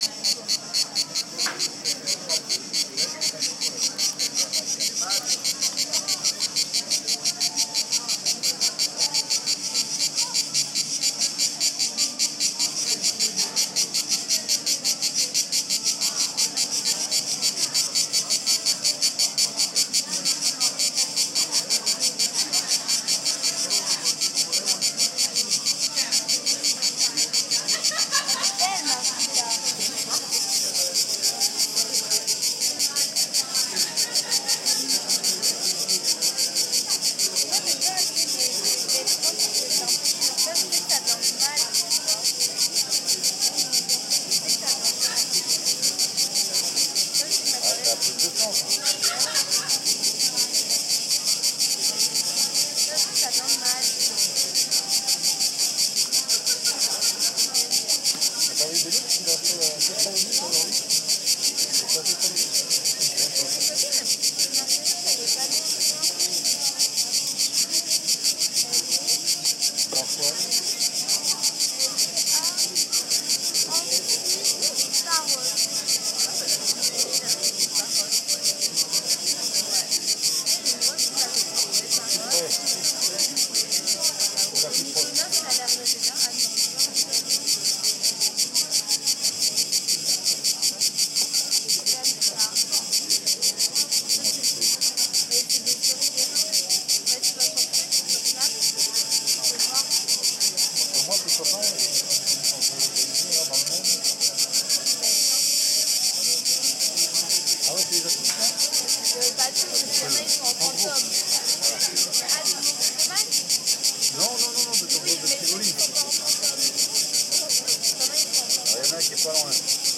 Cicale.m4a